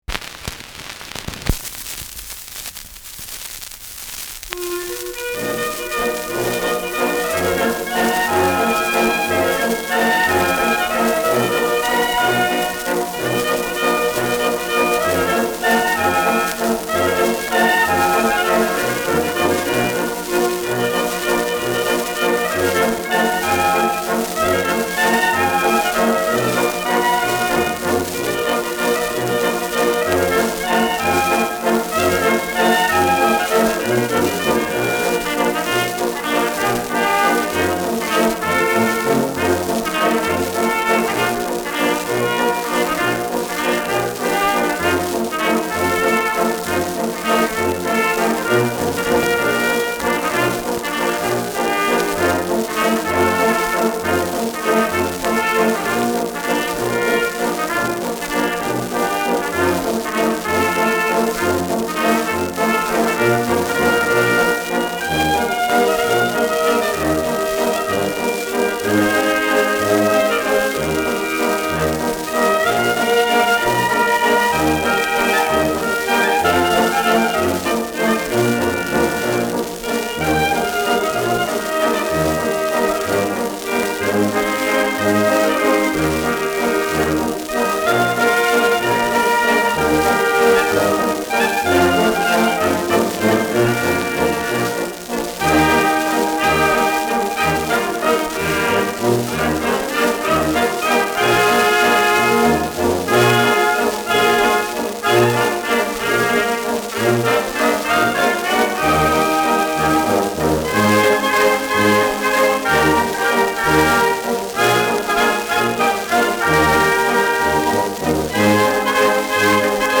Gemütliche Brüder : Ländler
Schellackplatte
Abgespielt : Erhöhtes Grundrauschen
[München] (Aufnahmeort)